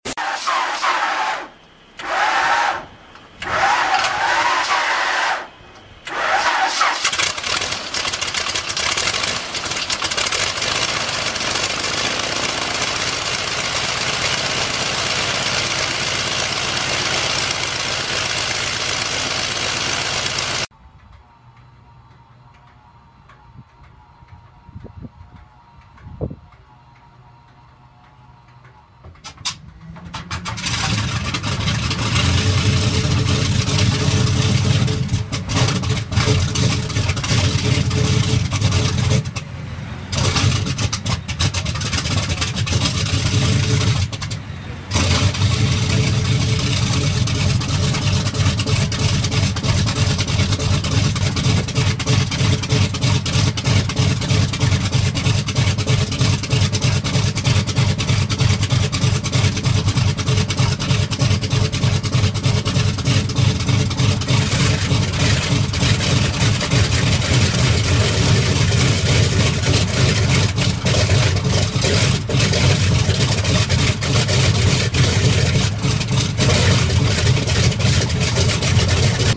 Big Old RADIAL AIRPLANE ENGINES sound effects free download
Big Old RADIAL AIRPLANE ENGINES Cold Start and Sound